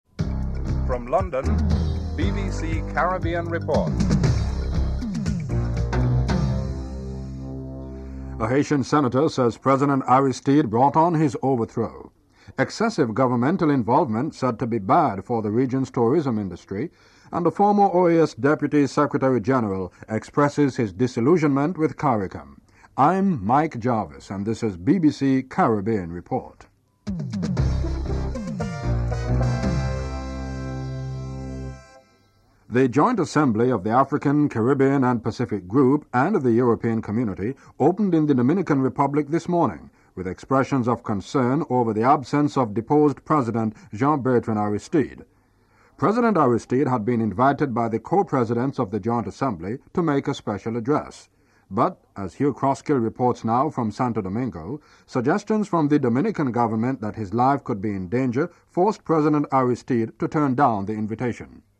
The British Broadcasting Corporation
1. Headlines (00:00-00:31)